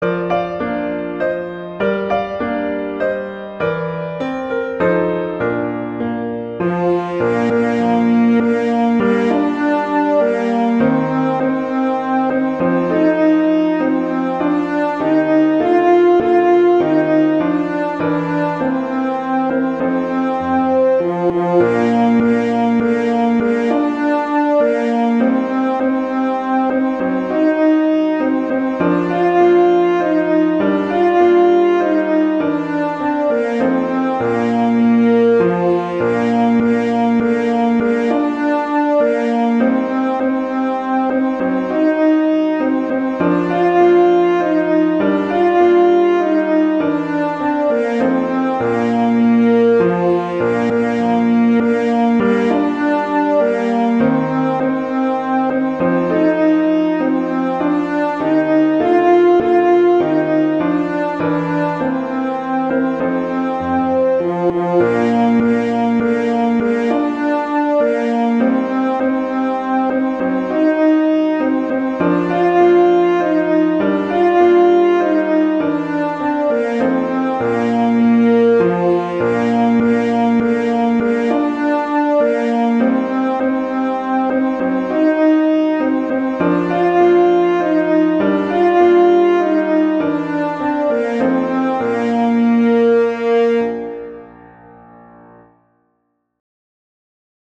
arrangements for horn and piano
traditional, irish, children